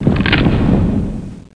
1 channel
islandFall.mp3